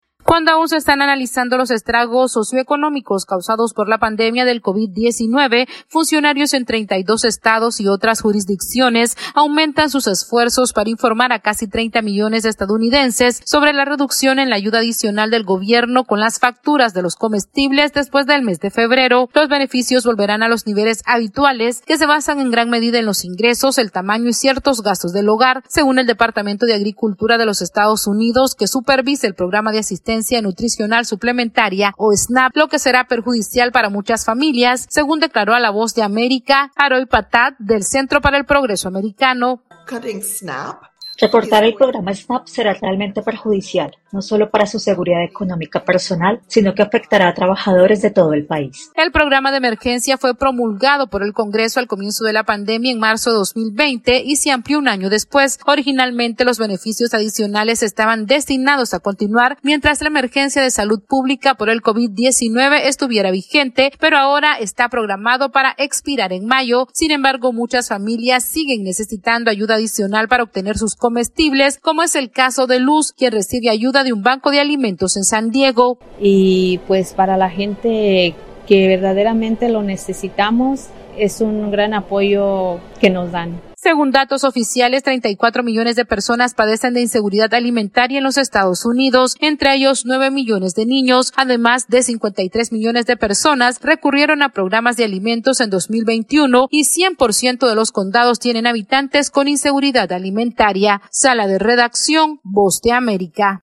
AudioNoticias
El gobierno de los Estados Unidos disminuirá la ayuda adicional que facilita para la compra de alimentos a más de 30 millones de personas por la crisis generada por la pandemia del COVID-19. Esta es una actualización de nuestra Sala de Redacción...